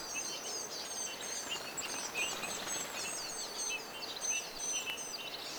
tuit-ääninen punatulkku
tuit-aaninen_punatulkkulintu.mp3